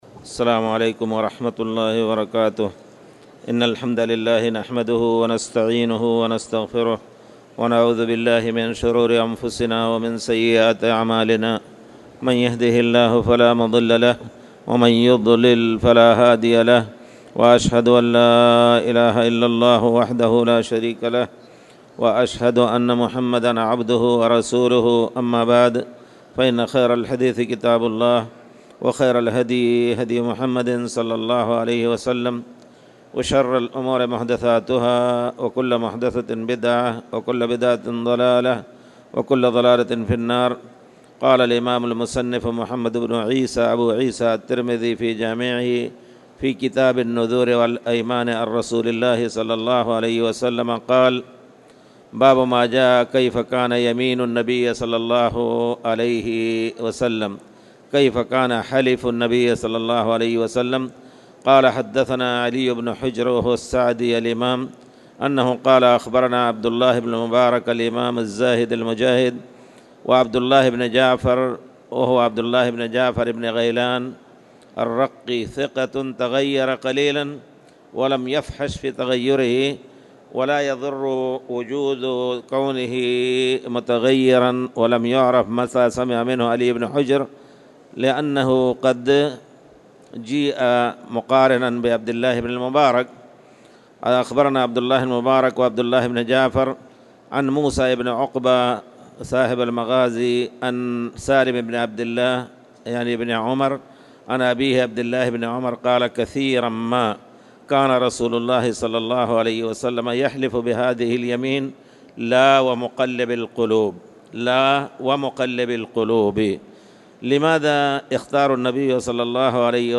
تاريخ النشر ١ رجب ١٤٣٨ هـ المكان: المسجد الحرام الشيخ